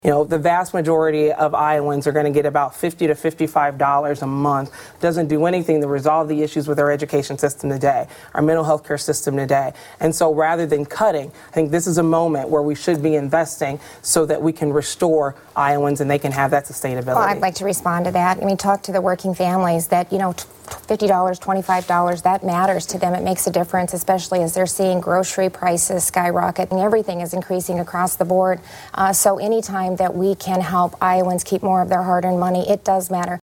abortion and carbon pipelines during their debate last night on Iowa PBS.